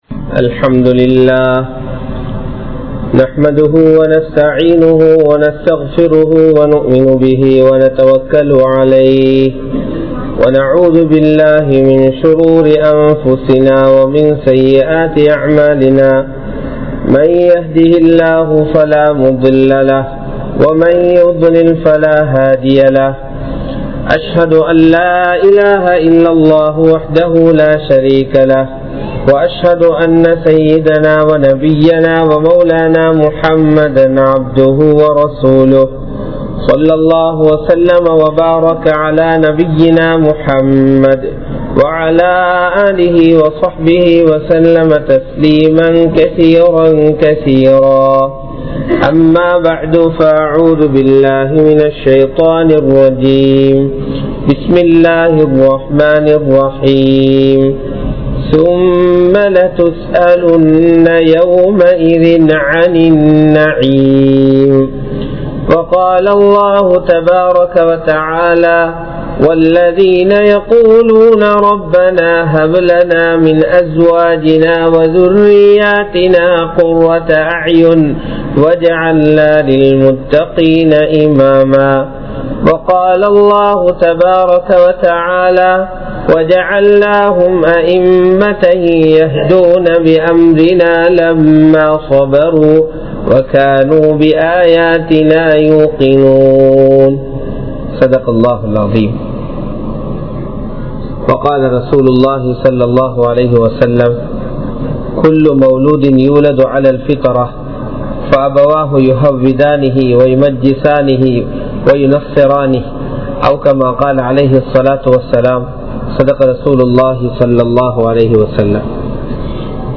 Pillaihalai Marantha Petroarhal(பிள்ளைகளை மறந்த பெற்றோர்கள்) | Audio Bayans | All Ceylon Muslim Youth Community | Addalaichenai
Majmaulkareeb Jumuah Masjith